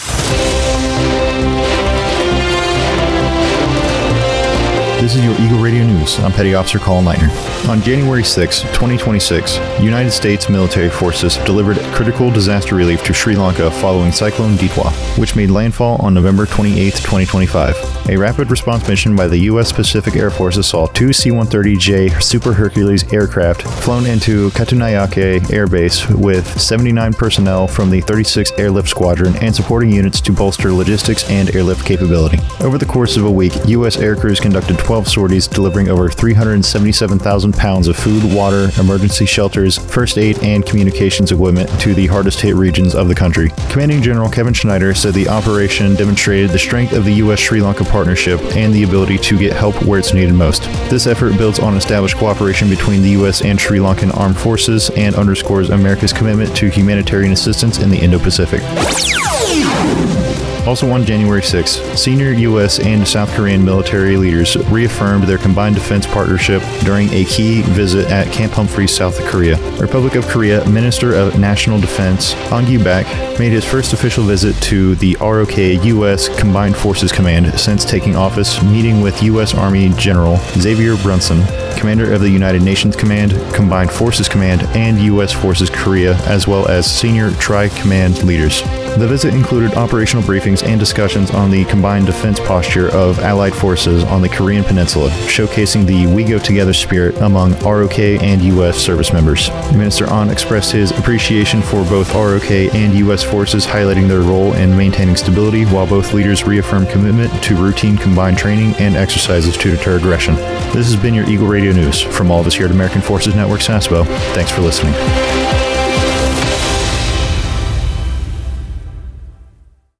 NEWSCAST 08JAN26: U.S. Humanitarian Aid to Sri Lanka & ROK Minister of Nation Defense Visits Camp Humphreys